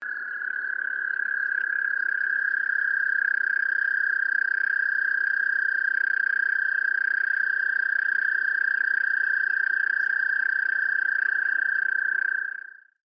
Single Frog Trill
Single Frog Trill is a free nature sound effect available for download in MP3 format.
Single Frog Trill.mp3